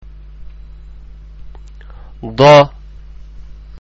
ഉച്ചാരണം (പ്ലേ ബട്ടണ്‍ അമര്‍ത്തുക)
015_Daad[1].mp3